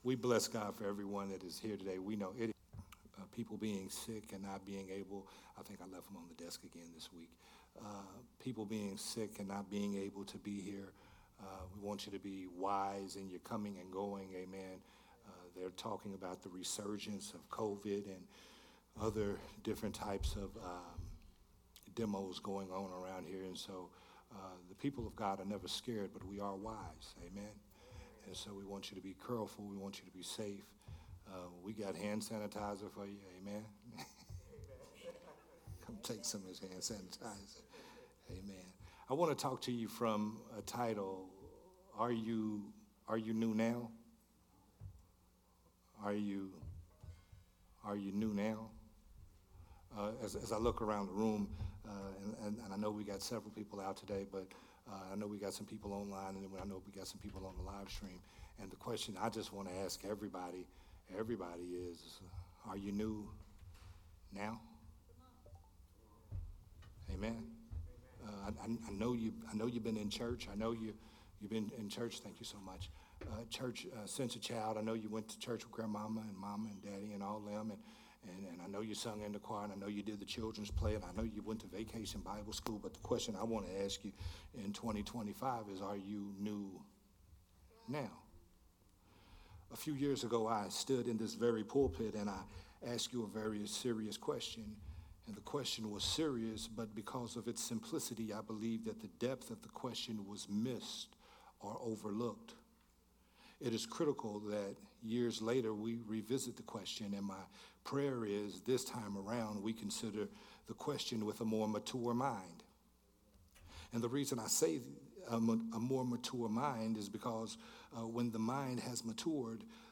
a sermon
Sunday Morning Worship Service